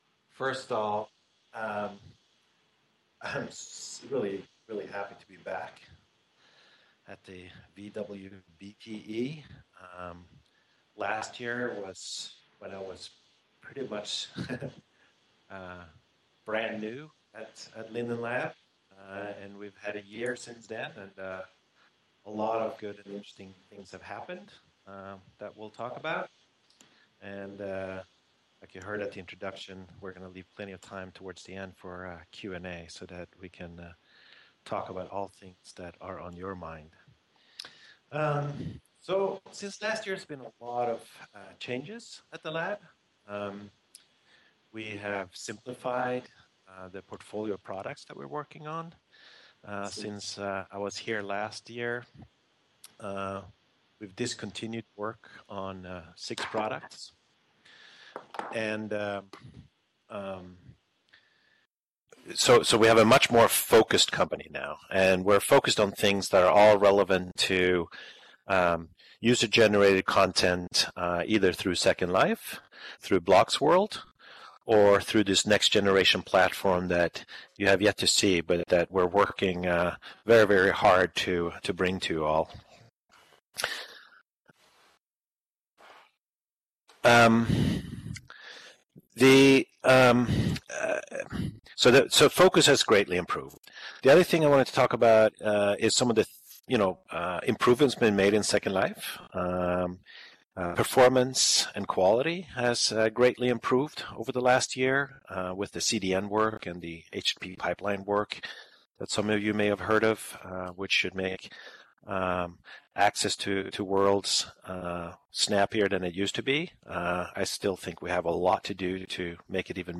On Wednesday, March 18th, Ebbe Altberg gave the keynote presentation at the 8th annual Virtual Worlds Best Practice in Education (VWBPE) conference, which runs from March 18th through 21st inclusive, in both Second Life and OpenSimulator.